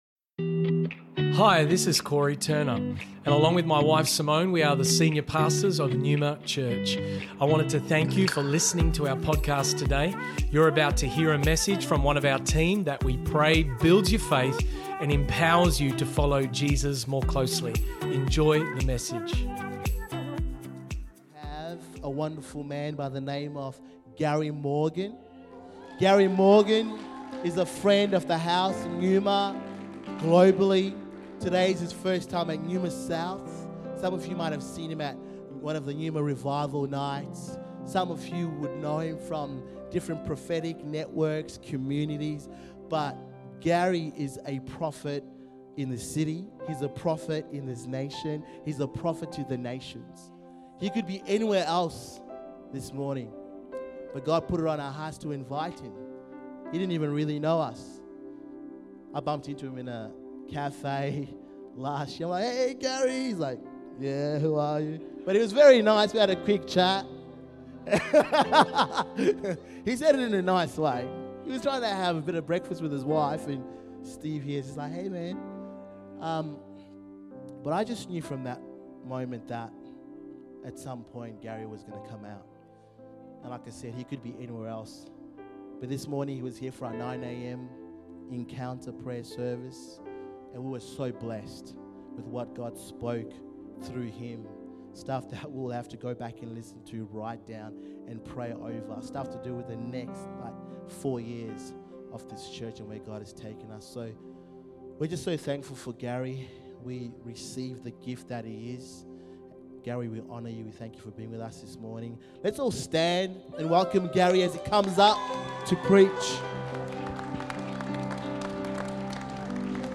Neuma Church Melbourne South Originally Recorded at the 10am Service on Sunday 15th October 2023.